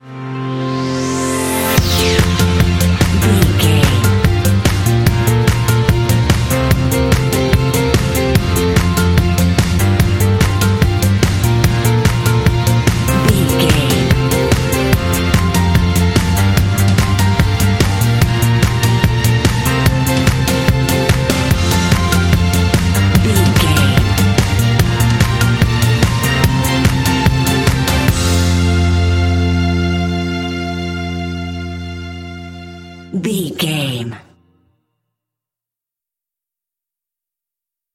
Bright and motivational music with a great uplifting spirit.
Ionian/Major
uplifting
bouncy
groovy
drums
strings
piano
electric guitar
bass guitar
rock
contemporary underscore